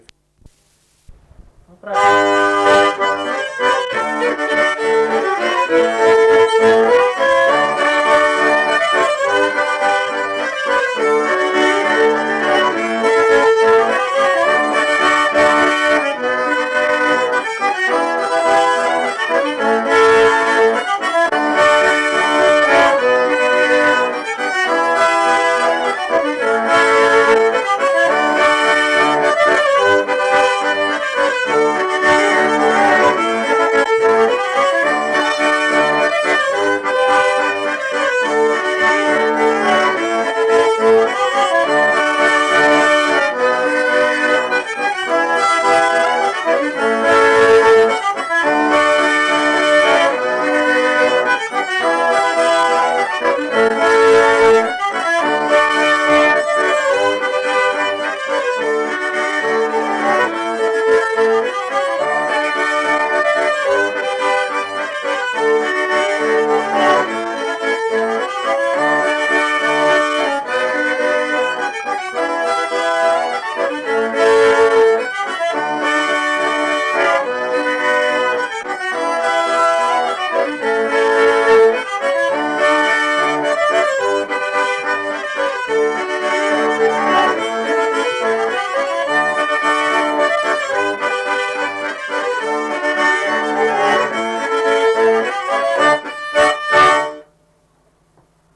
šokis
Atlikimo pubūdis instrumentinis
Instrumentas armonika klarnetas